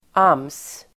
Uttal: [am:s]